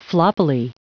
Prononciation du mot floppily en anglais (fichier audio)
Prononciation du mot : floppily